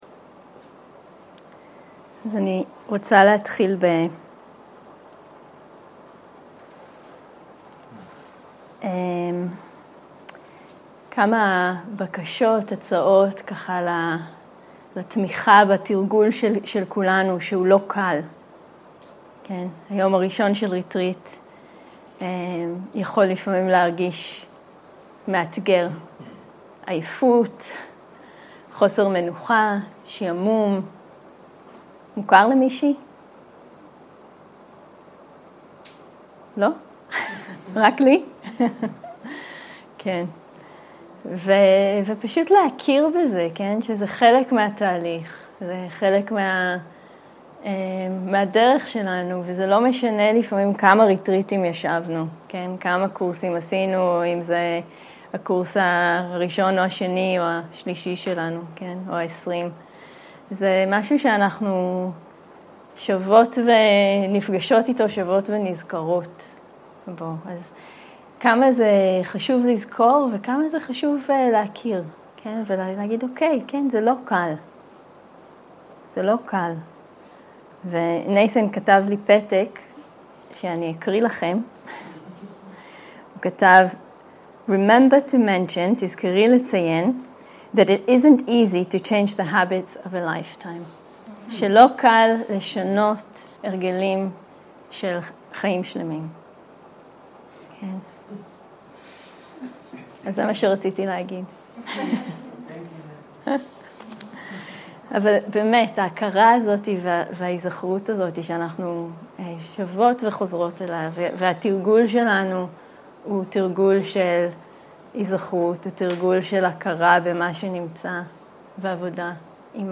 סוג ההקלטה: שיחות דהרמה
עברית איכות ההקלטה: איכות גבוהה תגיות